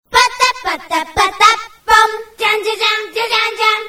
分类: 短信铃声
ysmsg信息声 特效音效